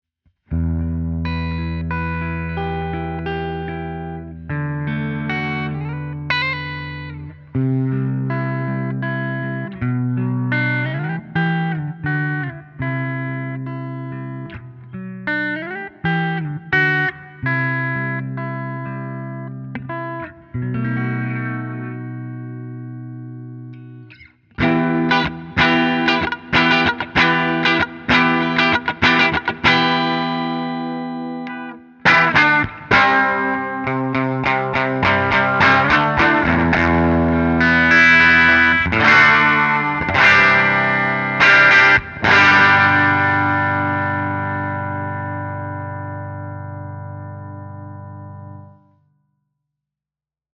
All audio clips are recorded with a Marshall JCM900 head, set on an extremely clean tone. The cabinet used is a 2×12 openback with Celestion Creamback 75 speakers.
Eastman T386 hollowbody (equipped with Kent Armstrong humbucker)
Clean sound, no effects added
Mode: Twin
Gain: 4/10
Twin-Humbucker.mp3